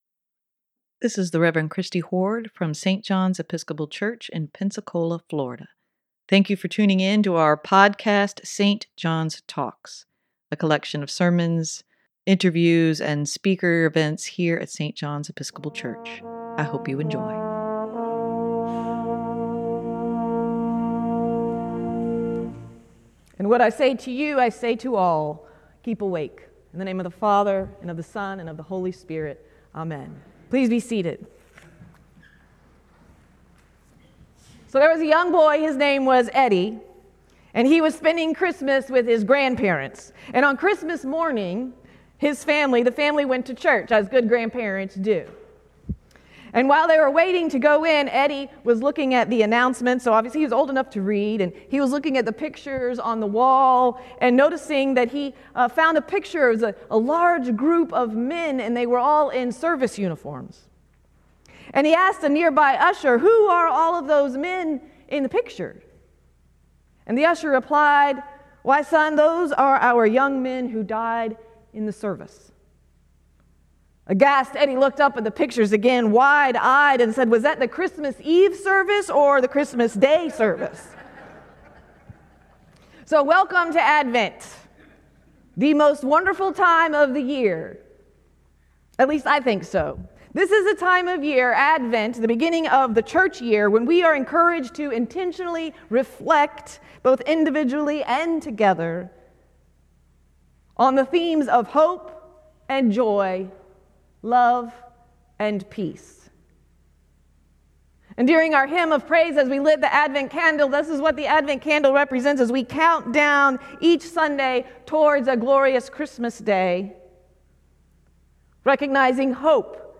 Sermon for Sunday, Dec. 3, 2023: Preparing our hearts to receive Jesus